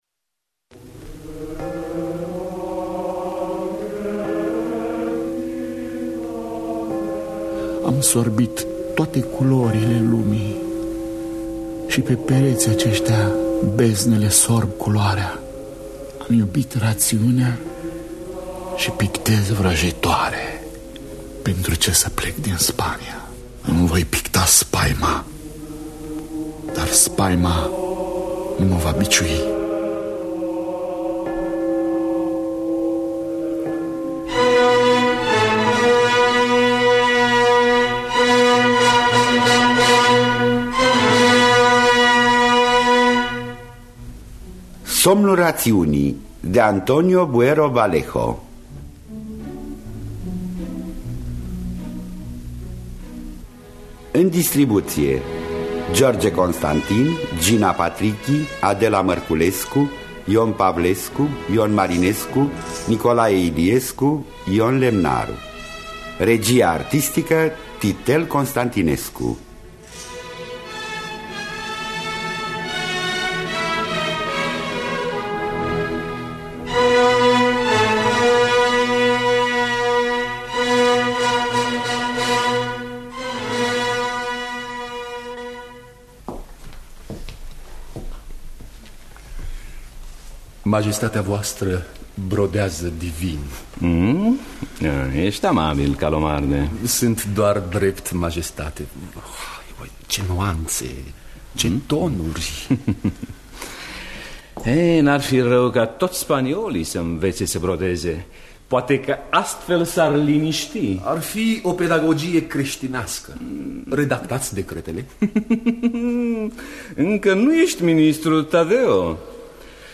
Somnul rațiunii de Antonio Buero Vallejo – Teatru Radiofonic Online